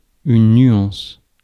Ääntäminen
France: IPA: [yn nɥɑ̃s]